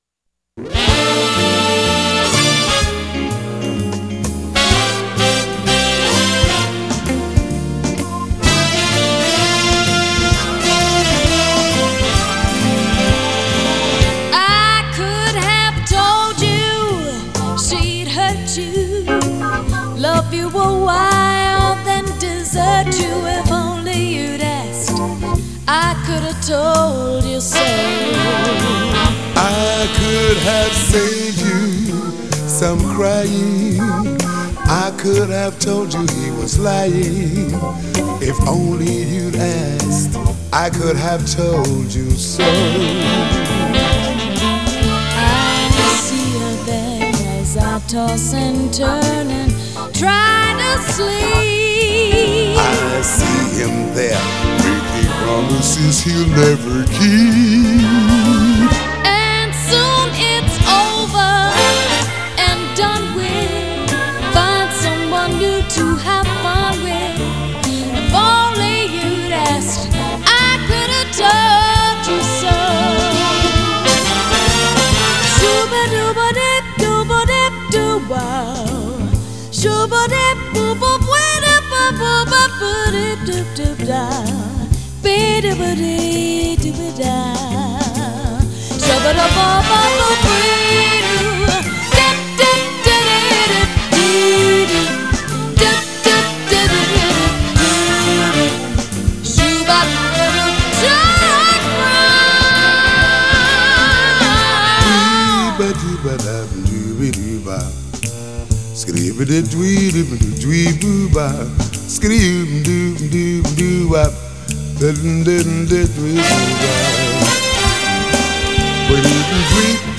앨범은 재즈 , 블루스 및 소울 표준으로 구성되며 솔로와 듀엣이 혼합되어 있습니다.